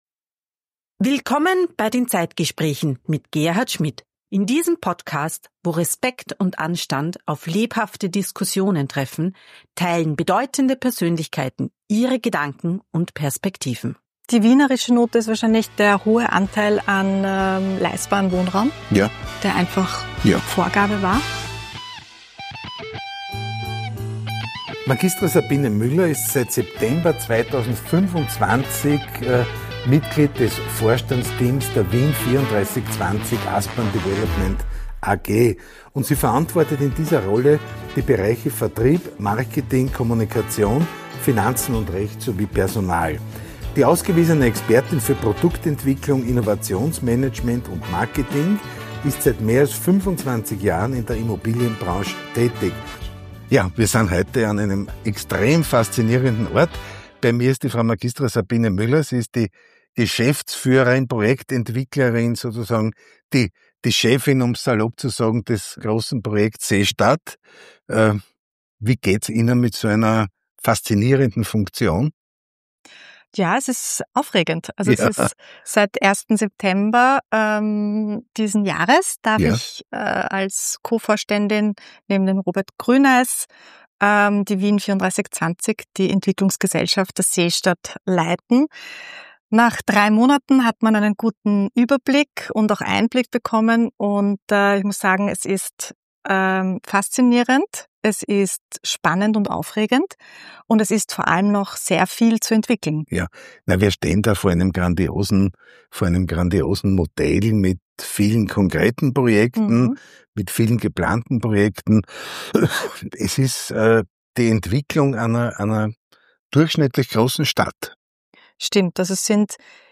Ein Schwerpunkt liegt auf der internationalen Strahlkraft der Seestadt, den Herausforderungen der Wirtschafts- und Standortentwicklung sowie auf Kunst im öffentlichen Raum und der Idee der Seestadt als Reallabor für die Stadt der Zukunft. Ein Gespräch über Stadt, die am Menschen orientiert ist – und darüber, was es braucht, damit ein neuer Stadtteil wirklich lebendig wird.